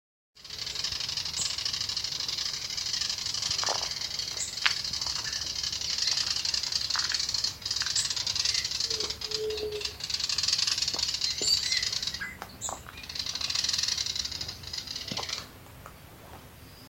Syndactyla rufosuperciliata acrita
English Name: Buff-browed Foliage-gleaner
Location or protected area: Parque Nacional El Palmar
Condition: Wild
Certainty: Observed, Recorded vocal